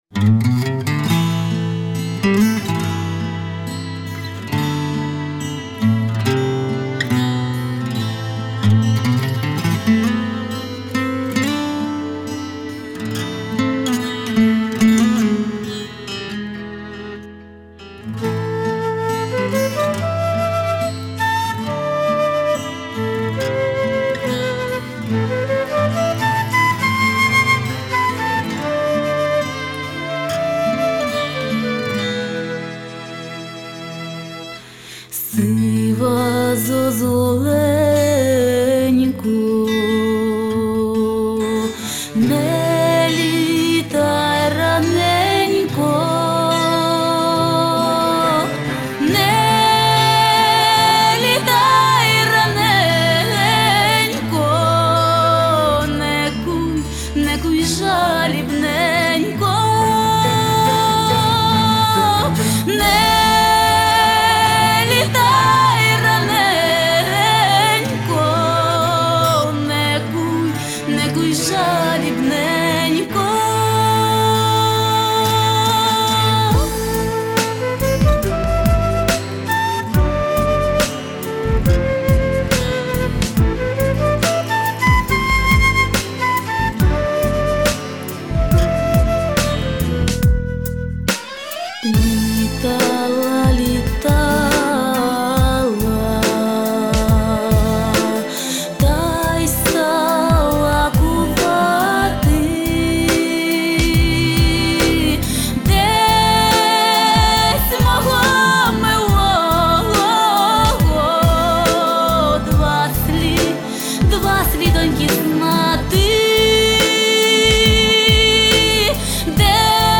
мішаний хор а cappella
Любовна пісня.